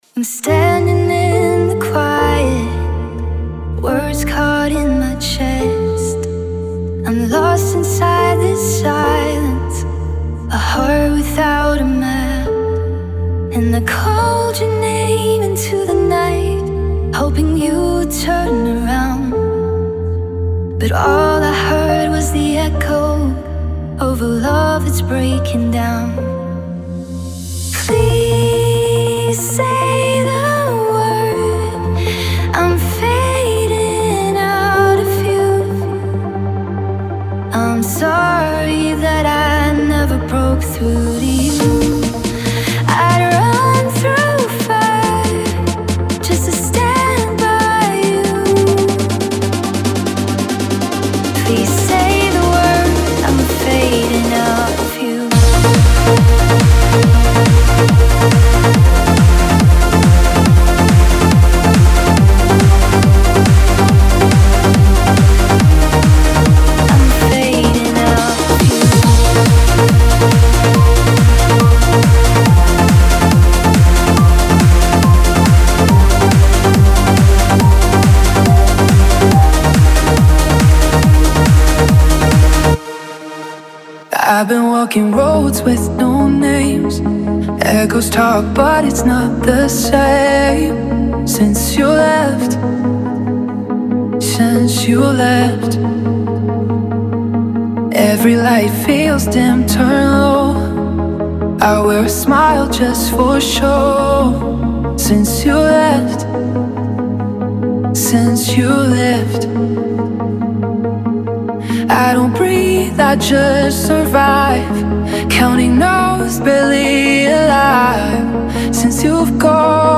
Type: Spire Midi Templates Samples
Trance Vocals
Kits 138 - 144 BPM & Key-Labelled.